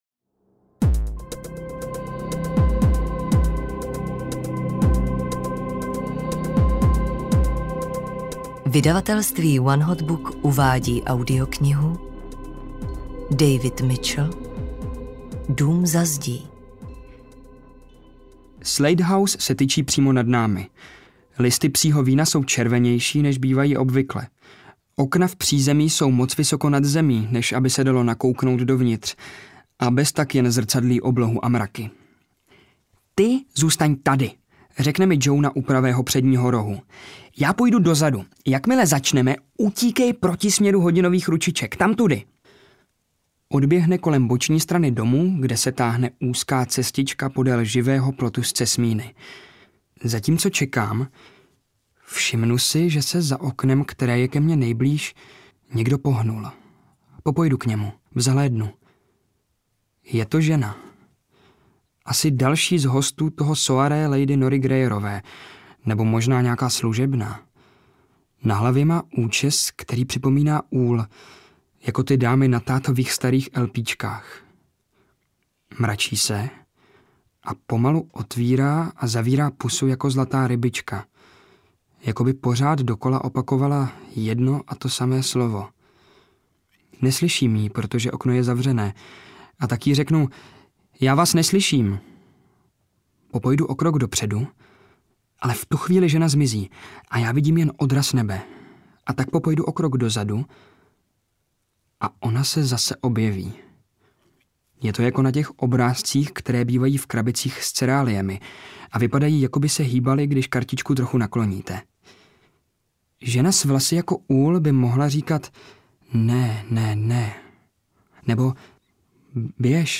Dům za zdí audiokniha
Ukázka z knihy